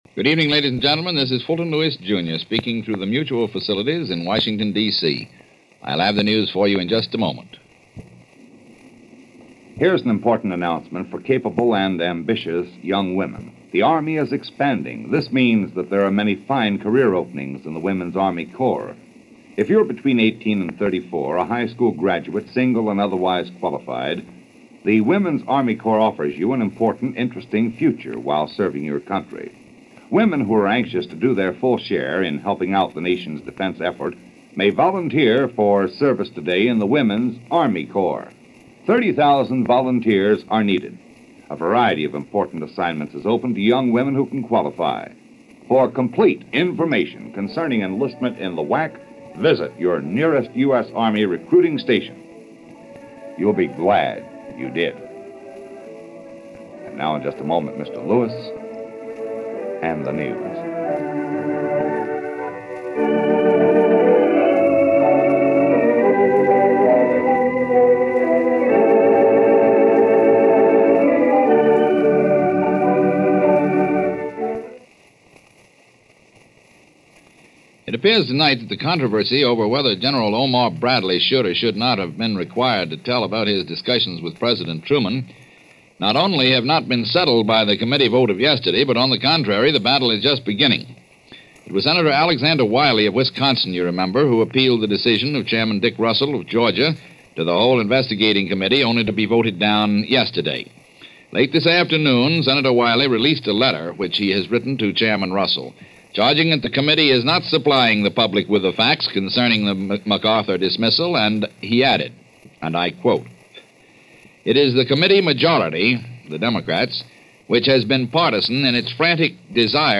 May 18, 1951 – MBS: Fulton Lewis Jr. News and Commentary